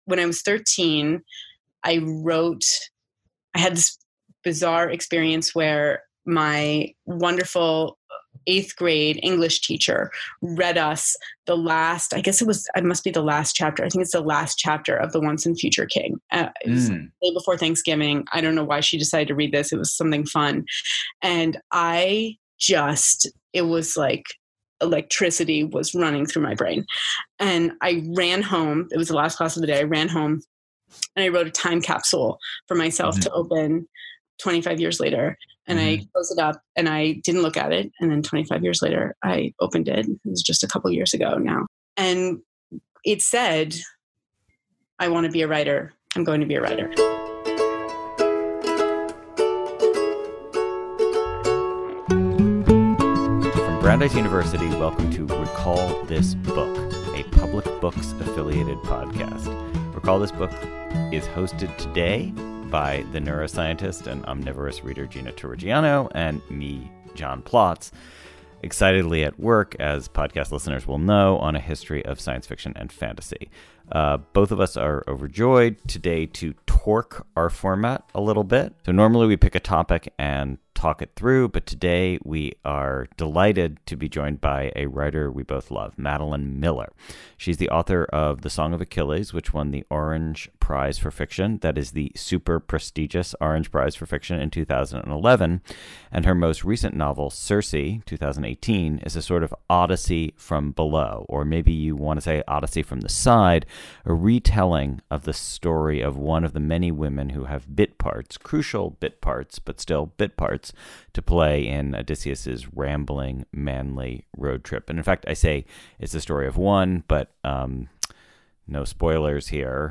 4 In Focus: An Interview with Madeline Miller about Circe